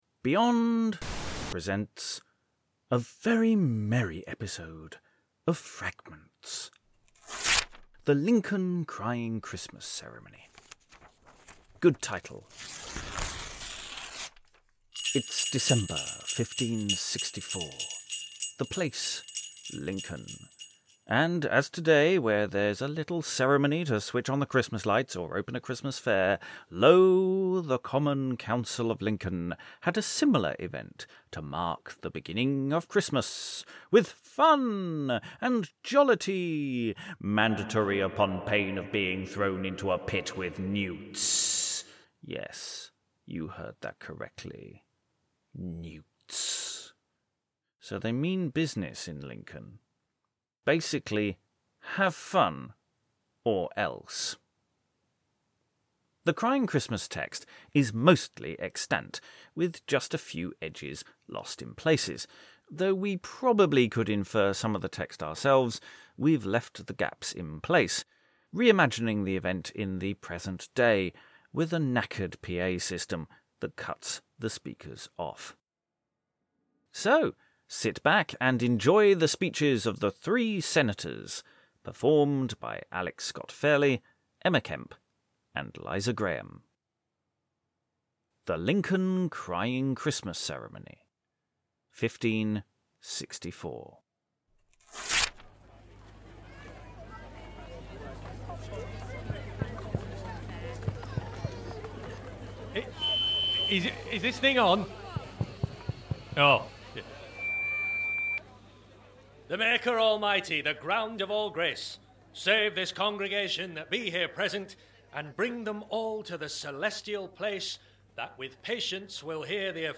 It’s December 1564, the place Lincoln – and as today where there’s a little ceremony to switch on the Christmas lights, or open a Christmas Fair, lo the Common Council of Lincoln had a similar event to mark the beginning of Christmas The Crying Christmas text is mostly extant, with just a few edges lost in places - we’ve left the gaps in place, reimagining the event in the present day with a knackered PA that cuts the speakers off.